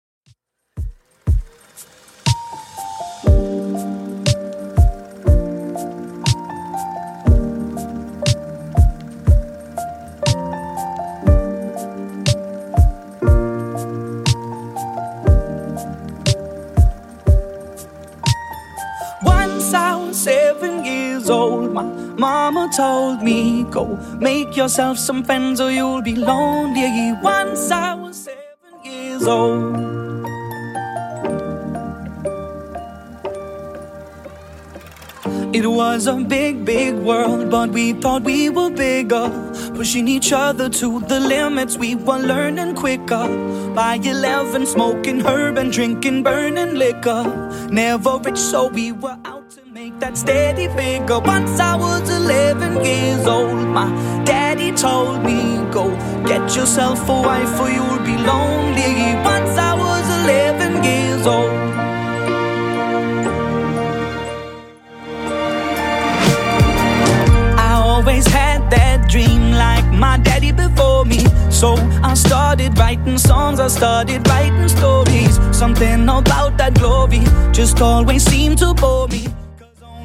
Genre: 80's
BPM: 100